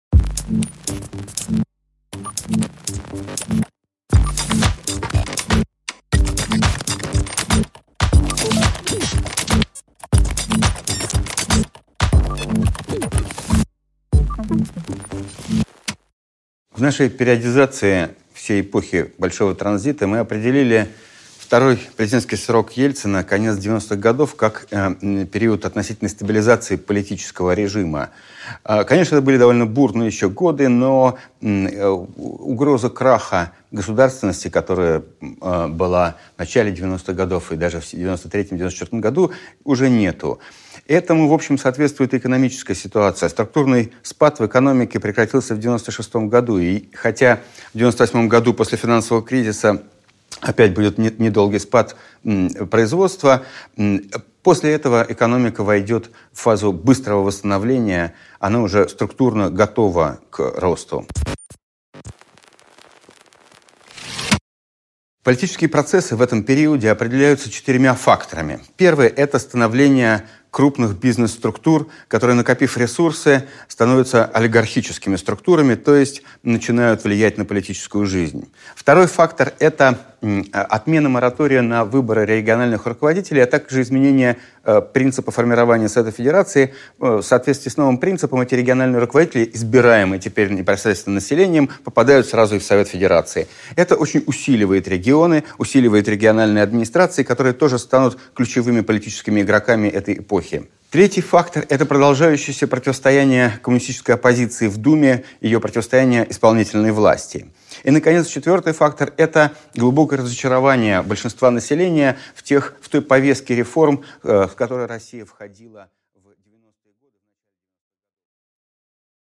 Аудиокнига Происхождение олигархии | Библиотека аудиокниг
Прослушать и бесплатно скачать фрагмент аудиокниги